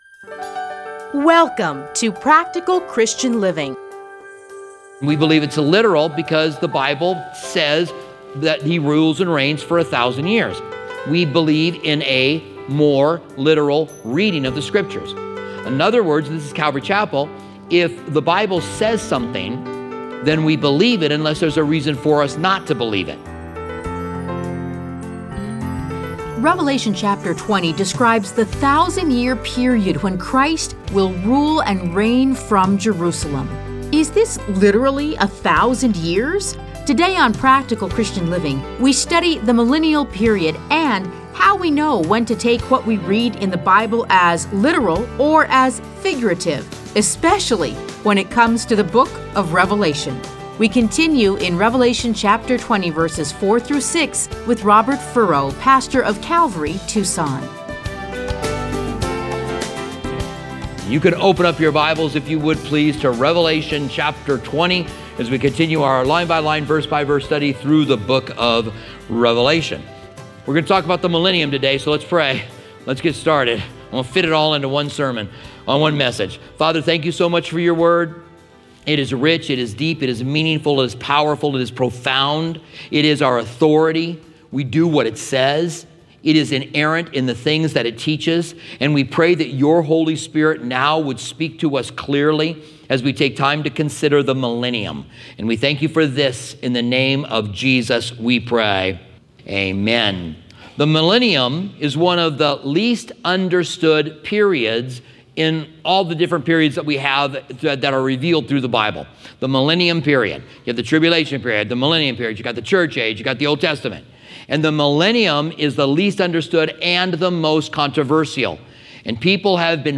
Listen to a teaching from Revelation 20:4-6.